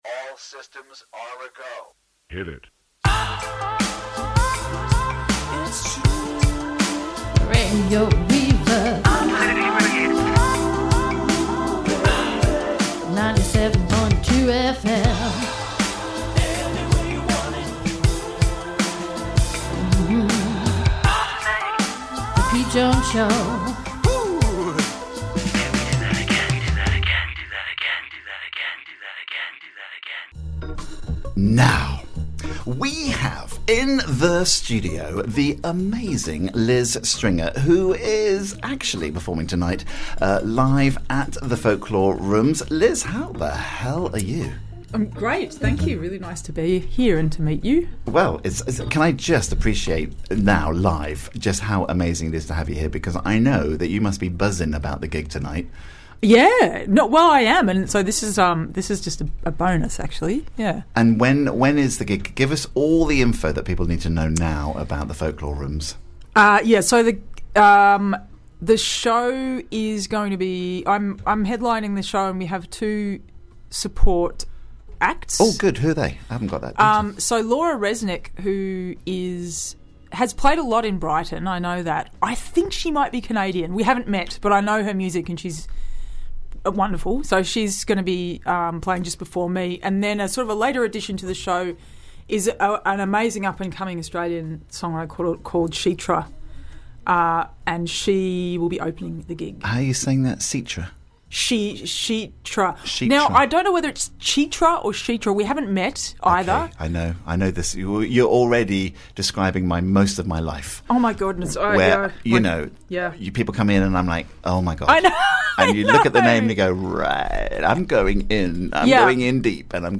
playing live in the studio